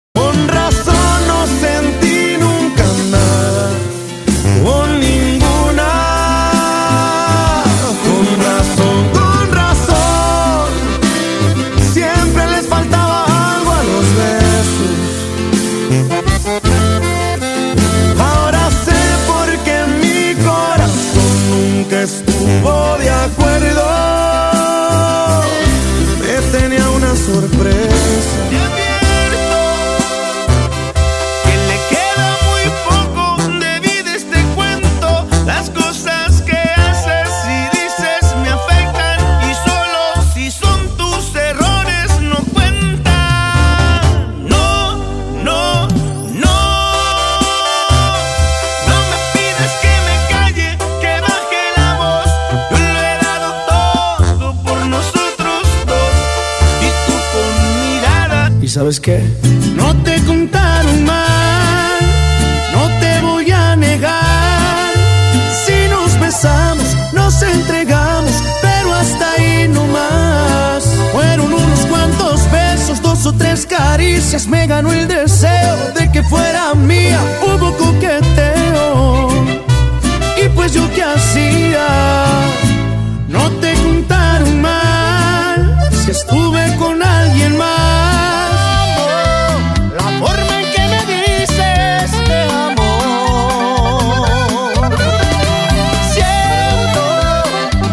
Regional Mexican Playlist
Regional Mexican Songs